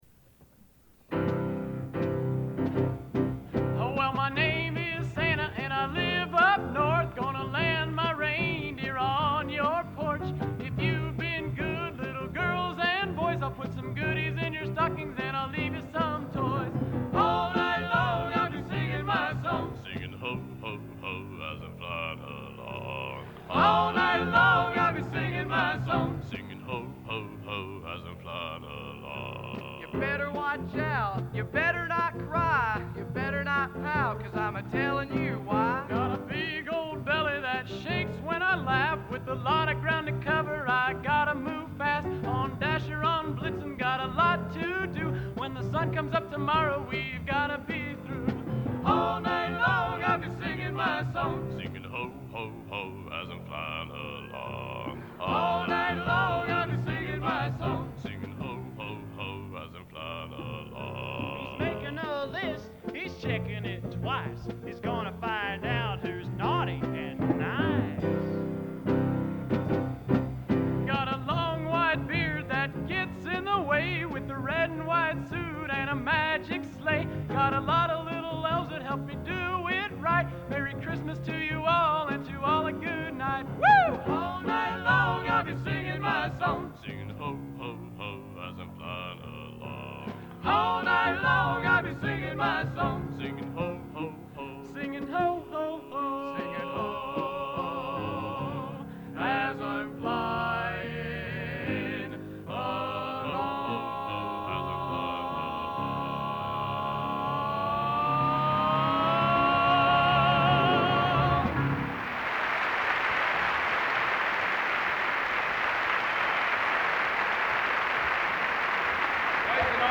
Genre: Popular | Type: Christmas Show |Specialty |